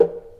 acoustic household percussion sound effect free sound royalty free Memes